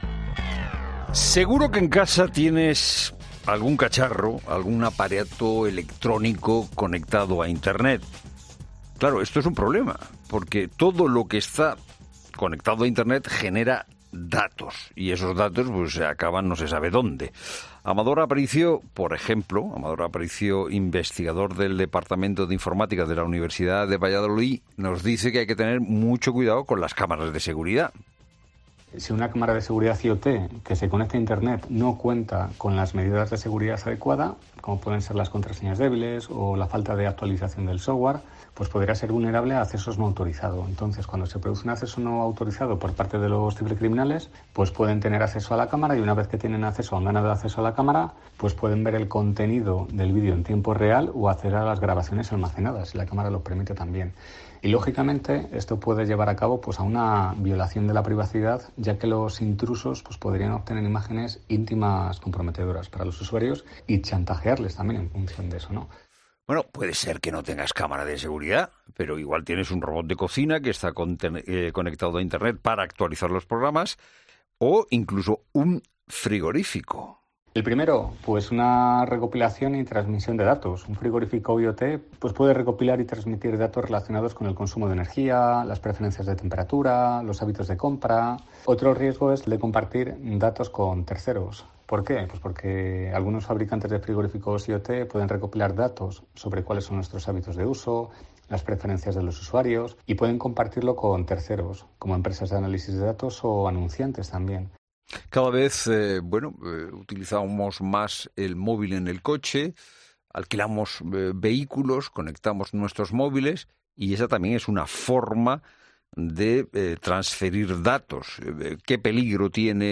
Varios expertos advierten en 'La Tarde' del problema que puede suponer para nuestra privacidad la cantidad de datos que poseen estos dispositivos conectados a Internet